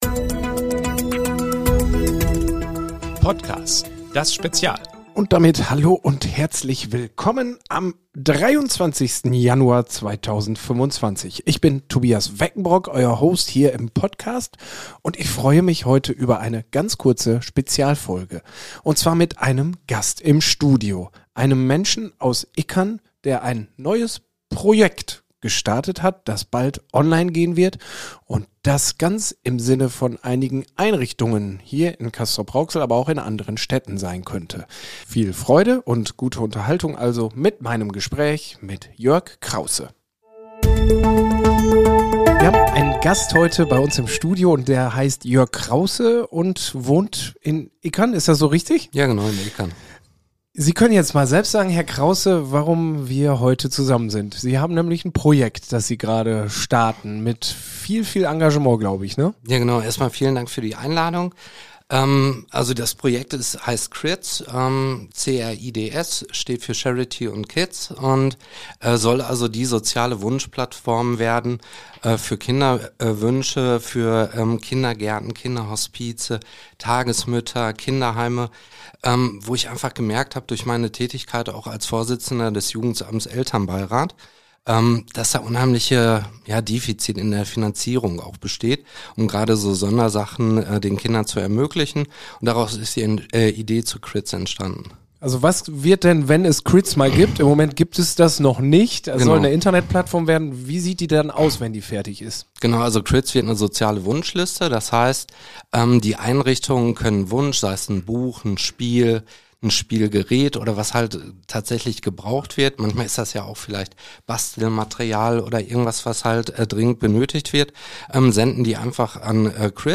Gründer der Charity-Plattform im Interview